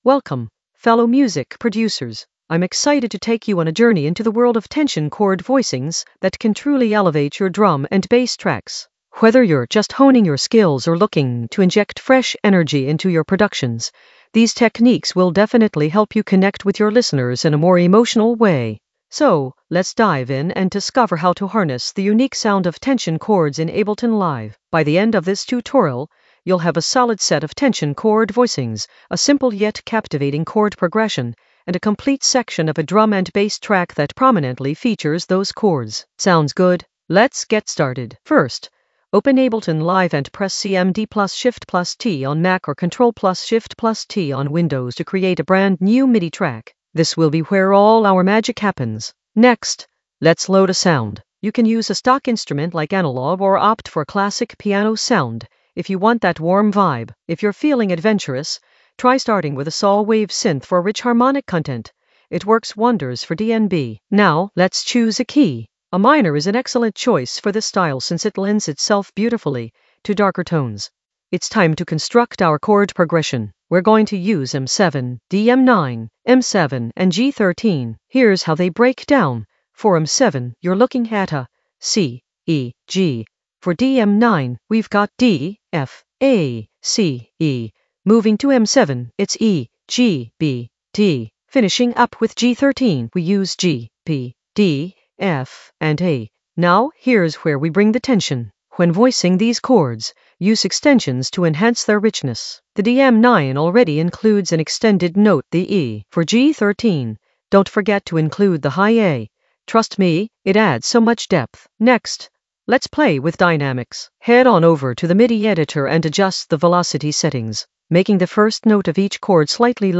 An AI-generated intermediate Ableton lesson focused on Tension chord voicings that actually works in the Composition area of drum and bass production.
Narrated lesson audio
The voice track includes the tutorial plus extra teacher commentary.